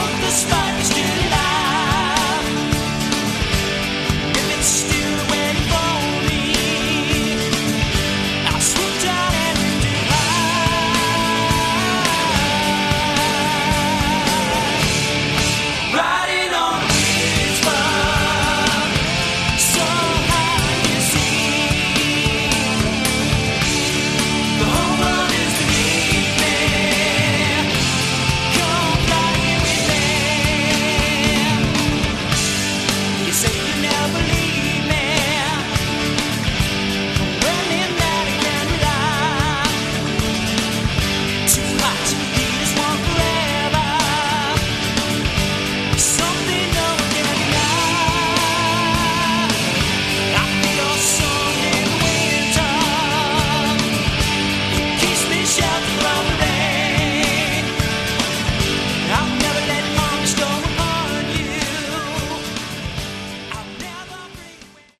Category: AOR
lead vocals, guitar
lead and rhythm guitar, backing vocals
drums
additional keyboards